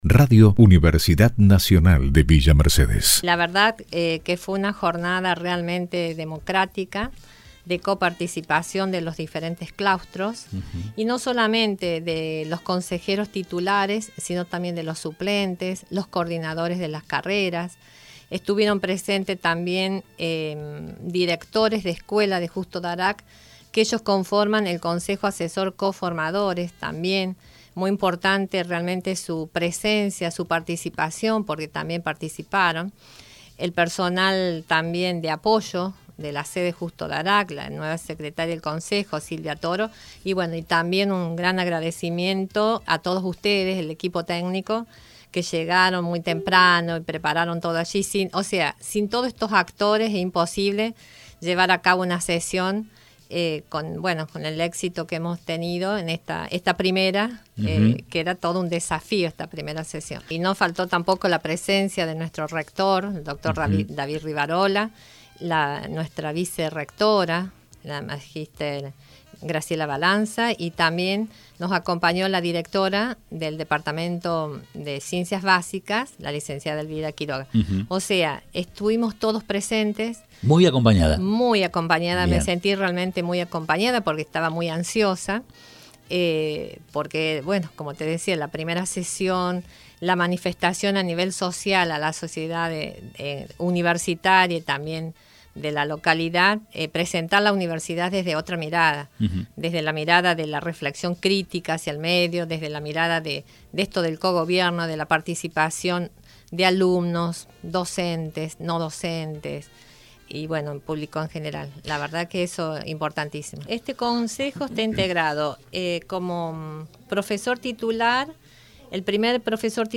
Justo Daract: Primera sesión del consejo de Escuela de Ciencias Sociales y Educación